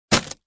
brick_crack.mp3